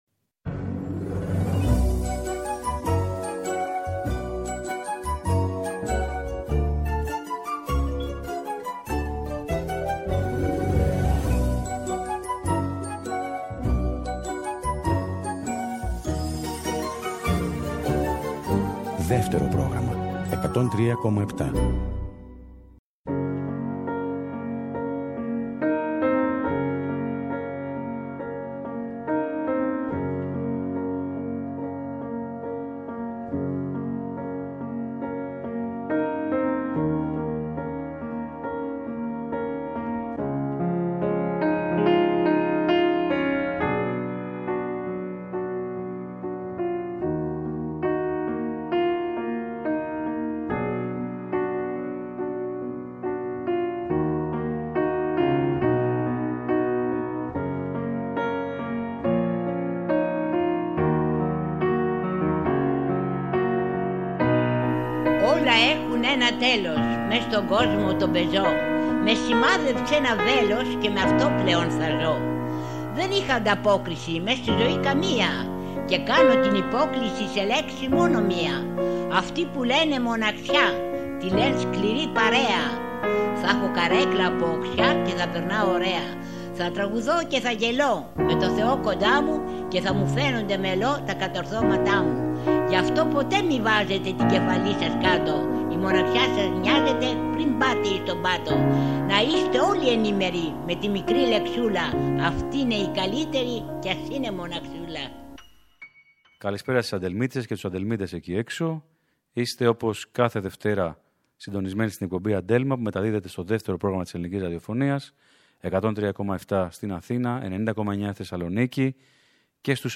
η “Αντέλμα” γιορτάζει την Ευρωπαϊκή Ημέρα της μουσικής και διοργανώνει μια μίνι συναυλία στο στούντιο Ε της ελληνικής ραδιοφωνίας.
πιάνο
Τραγουδούν όλοι μαζί στο στούντιο και παίζουν όλα τα όργανα οι ίδιοι.